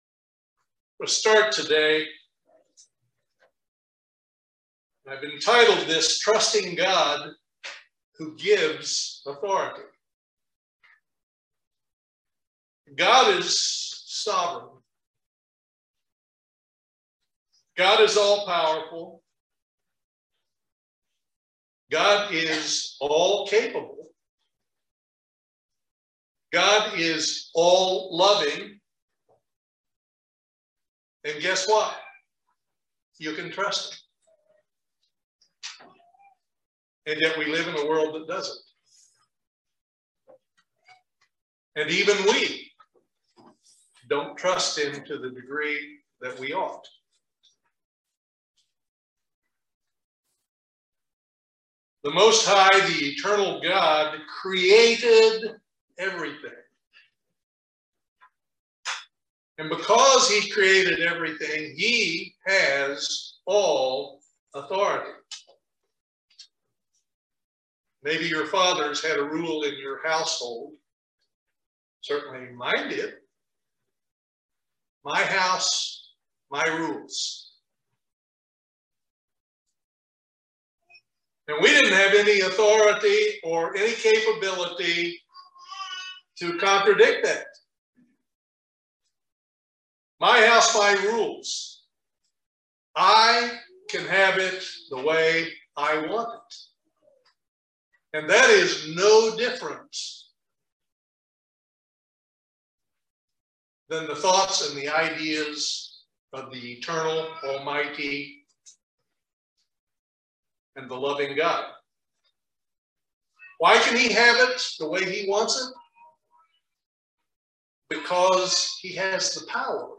This sermon reminds us about trusting God and how He has authority over everything. Sometimes we have to meditate on whether we really trust God , and do we trust Him enough.
Given in Lexington, KY